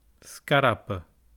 Pronunciation[ˈs̪kaɾapə]